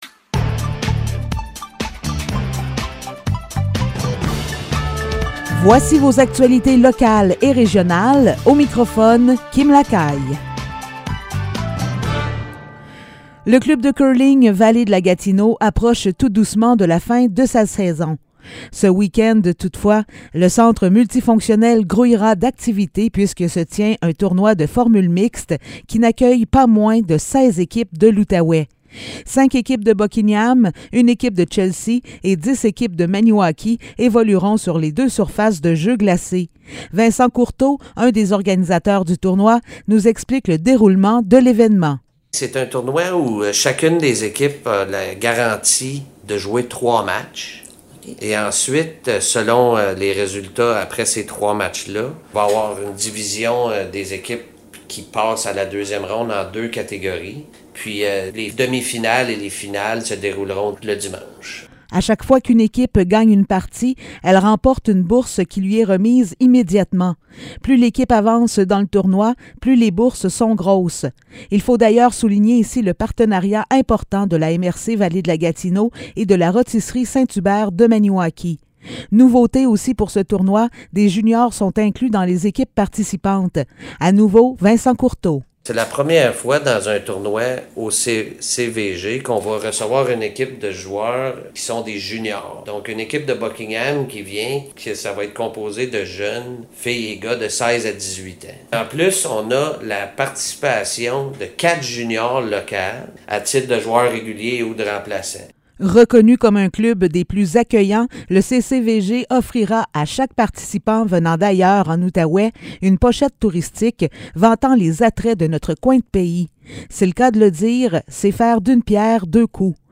Nouvelles locales - 7 avril 2022 - 15 h